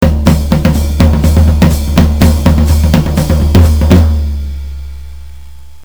[影视音效][混合敲击爵士鼓声音][剪辑素材][免费音频素材下载]-8M资料网